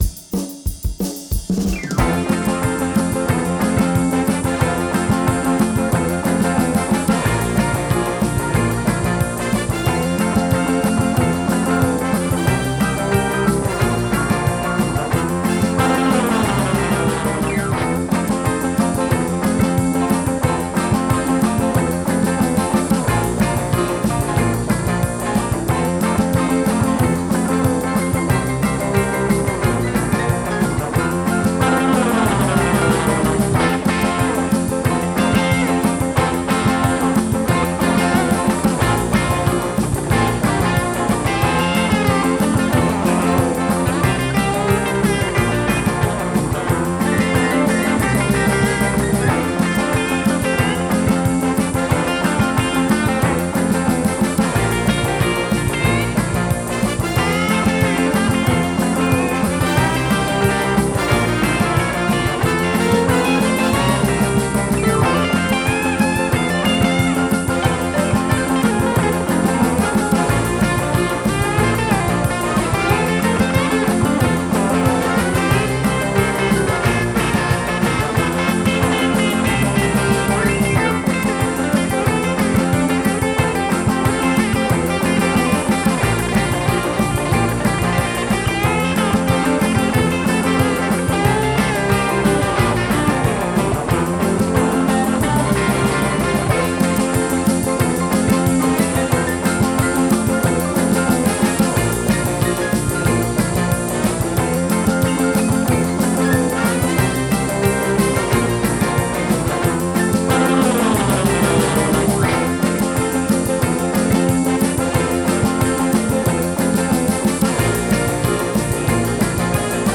pop
rock
sintetizador